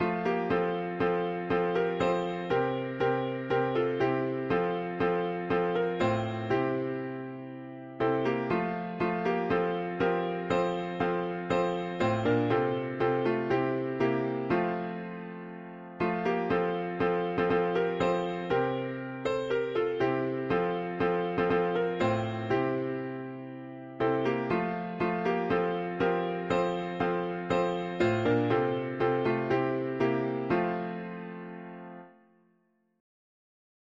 I’m bound for the promised land, … english theist 4part
Key: F major Meter: CM with refrain